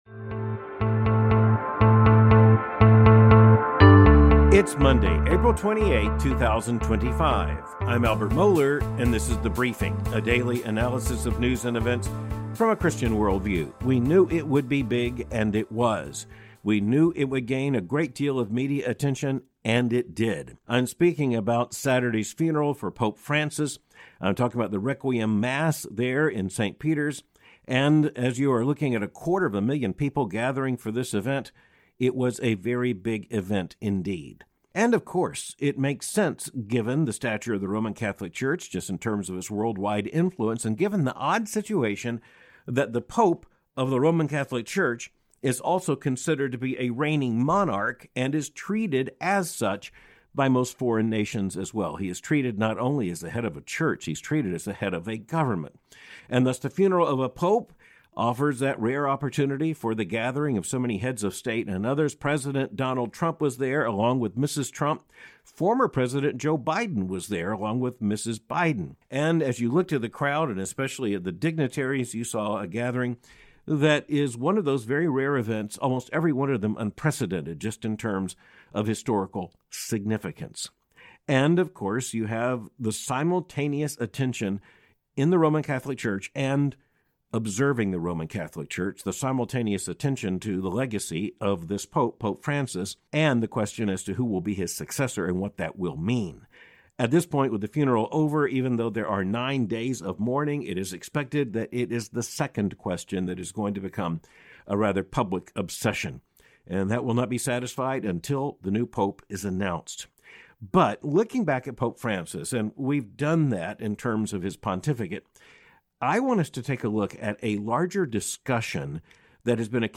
1 Monday, April 28, 2025 25:17 Play Pause 9h ago 25:17 Play Pause Play later Play later Lists Like Liked 25:17 This is The Briefing, a daily analysis of news and events from a Christian worldview. Part I (00:13 - 03:13) The Liberal Challenge: The Death of Pope Francis Raises Huge Questions With Big Lessons for Both Catholics and Protestants Pope Francis’ Legacy in the U.S.: A More Open, and Then Divided, Church by The New York Times (Elizabeth Dias and Ruth…